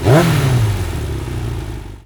motorcycle-rev-03.wav